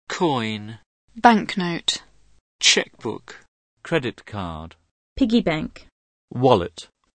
Angielski "At once!" to nowoczesny, profesjonalnie zrobiony program, którego zaletą jest rozbudowany materiał multimedialny: specjalnie kręcone w Wielkiej Brytanii filmy wideo, setki oryginalnych zdjęć i nagrania profesjonalnych lektorów.
Przykładowe nagrania słówek  359KB